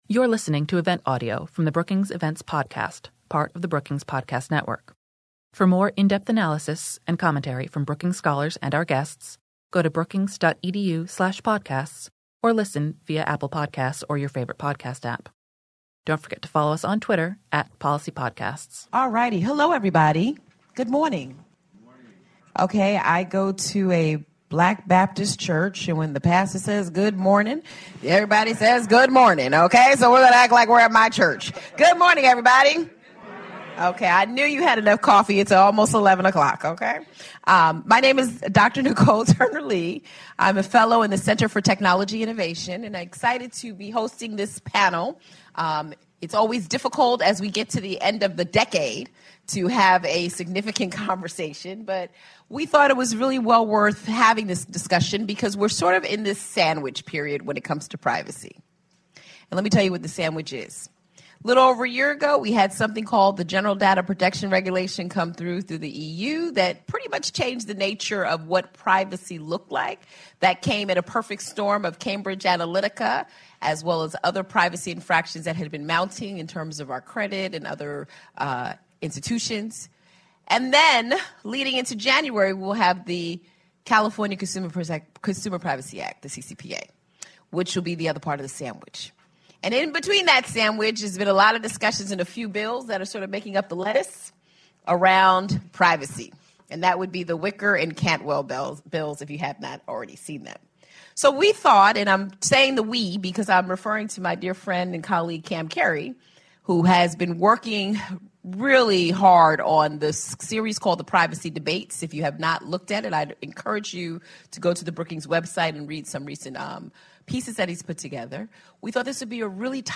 On December 13, the Center for Technology Innovation at Brookings hosted a public discussion on how the GDPR and CCPA affect U.S. businesses and individuals. How will corporations adapt to the changing regulatory environment, and what could the GDPR and CCPA mean for the outlook of federal privacy legislation? After the session, panelists answered questions from the audience.